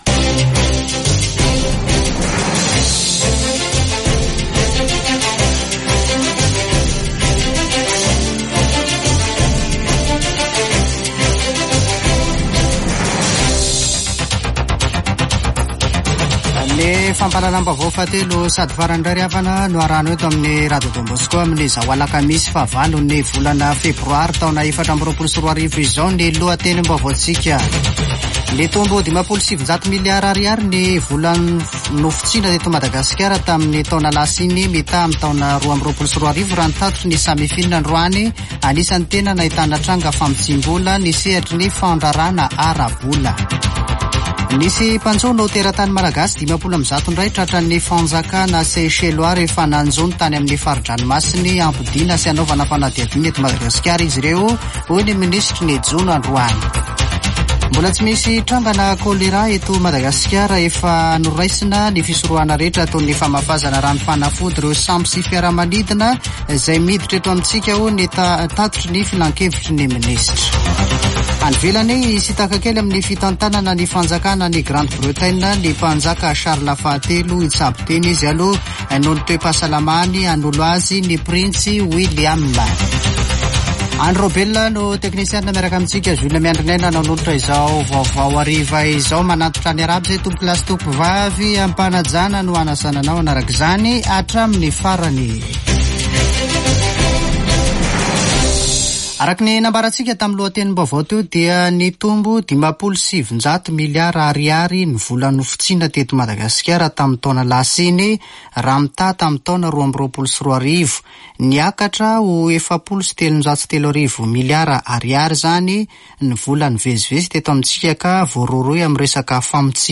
[Vaovao hariva] Alakamisy 8 febroary 2024